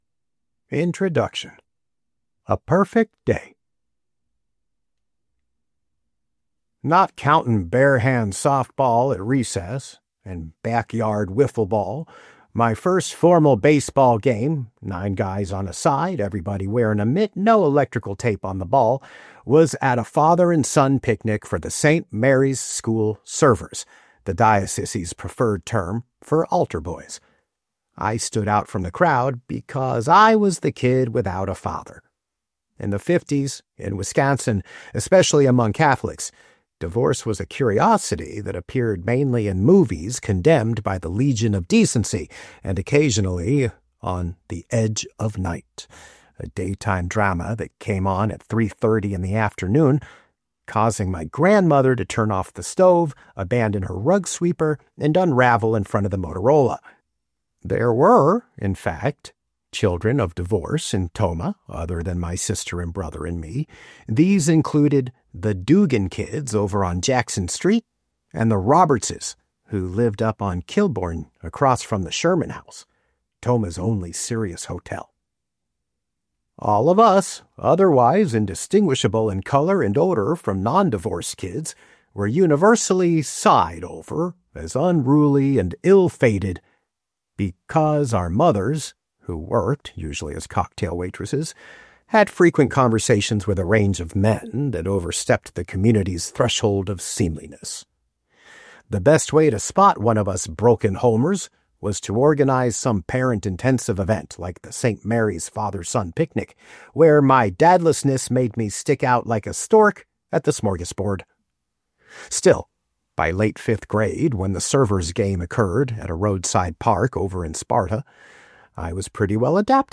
• Audiobook • 11 hrs, 11 mins